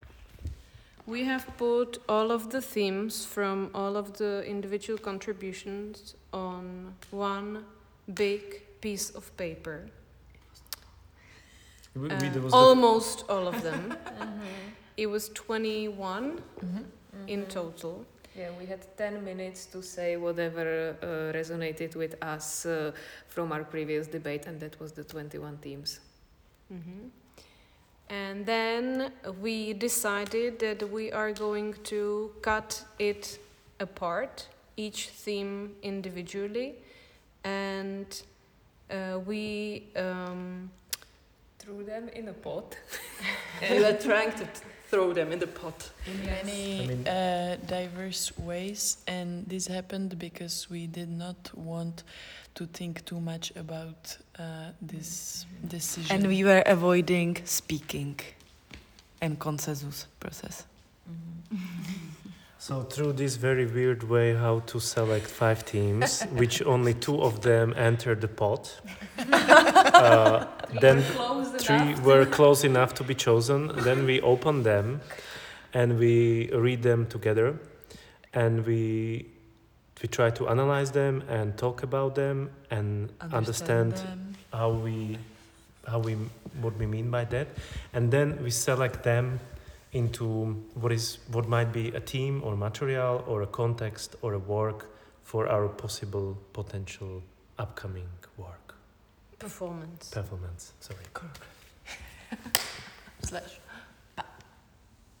Within five minutes, we gathered 21 topics on a large sheet, then cut, crumpled, and threw them into a pot to decide playfully — without overthinking or long discussions.
We chose this nonverbal method to avoid consensus and let chance and movement shape our decision.